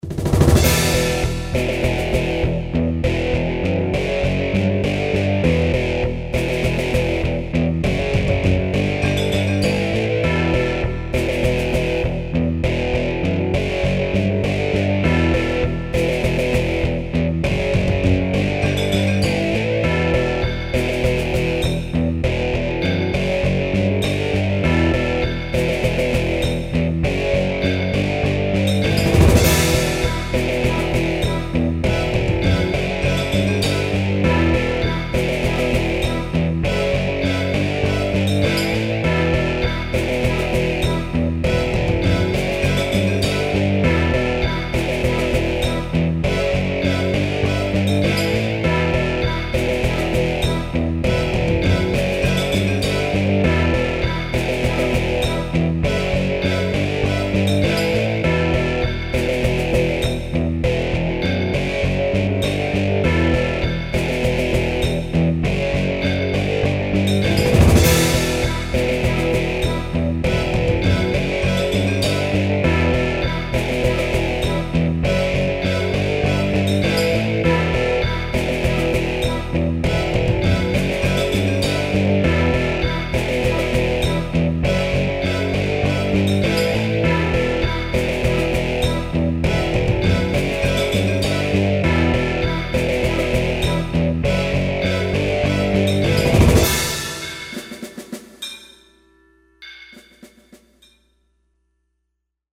cover/remix